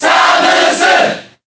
File:Zero Suit Samus Cheer Japanese SSBB.ogg
Zero_Suit_Samus_Cheer_Japanese_SSBB.ogg